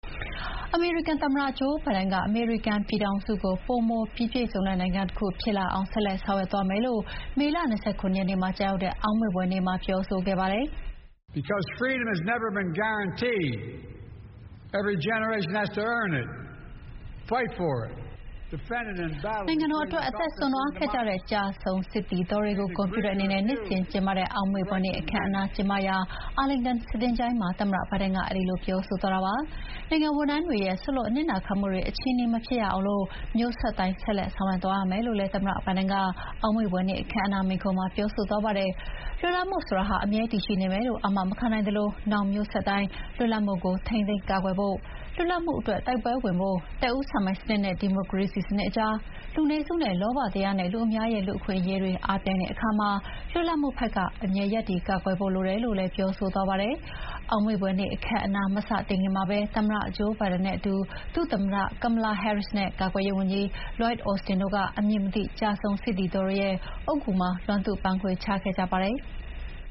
အောက်မေ့ဖွယ်နေ့အခမ်းအနား သမ္မတဘိုင်ဒန်တက်ရောက် မိန့်ခွန်းပြော